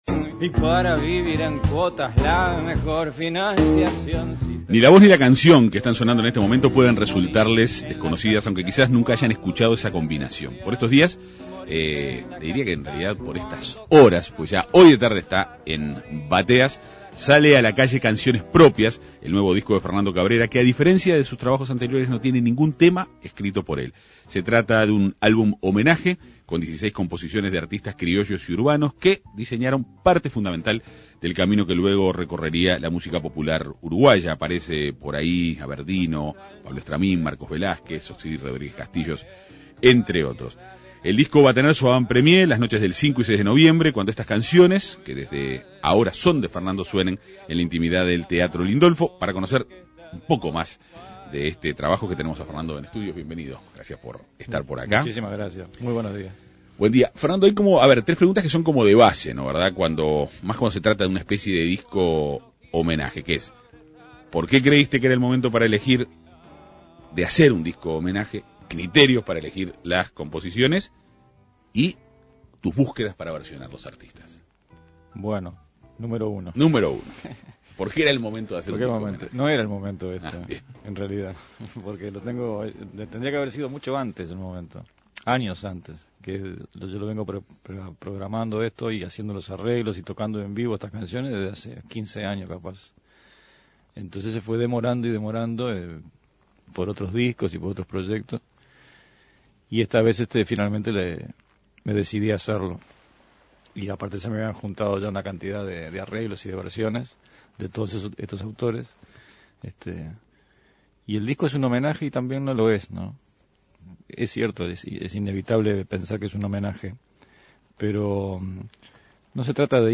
Es un álbum homenaje con 16 composiciones de artistas criollos y urbanos que diseñaron el camino que luego recorrería la música popular uruguaya. El artista dialogó en la Segunda Mañana de En Perspectiva.